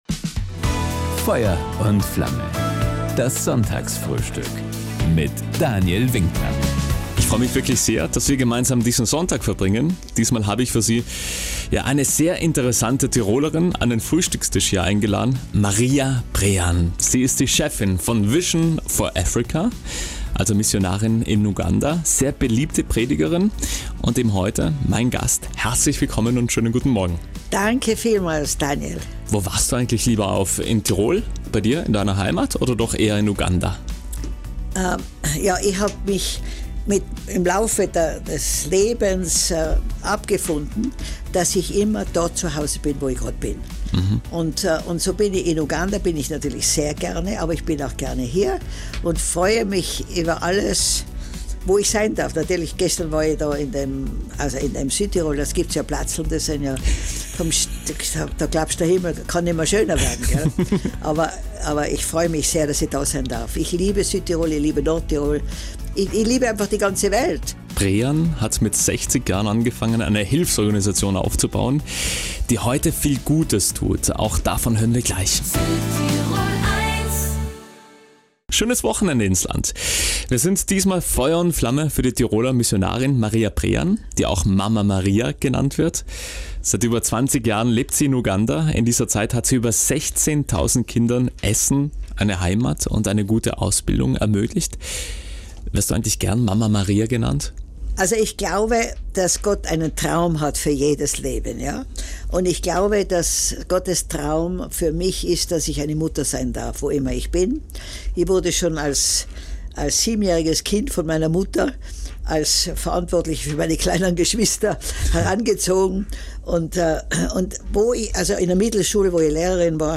An ihrer besonderen Geschichte hat sie uns diesmal im Sonntagsfrühstück „Feuer und Flamme“ auf Südtirol 1 teilhaben lassen.